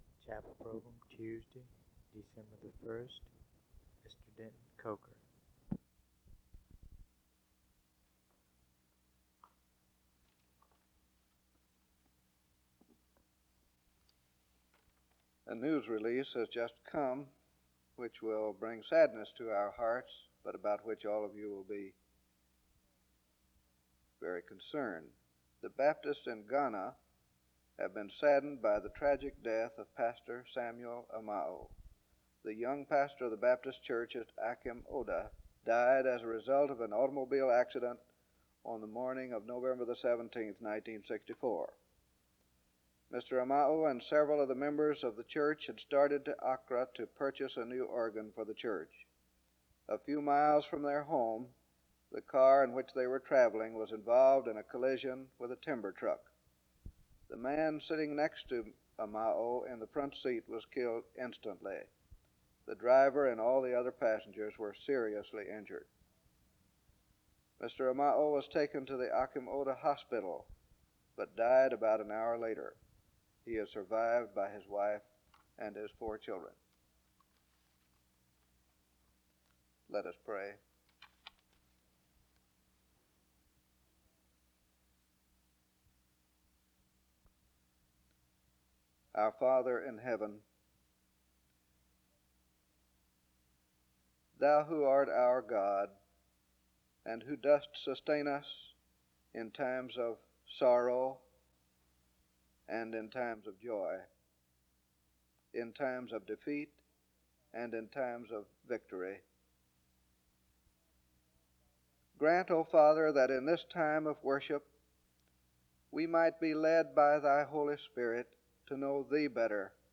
The service begins with an announcement and prayer from 0:00-3:27. There is music from 3:28-8:09.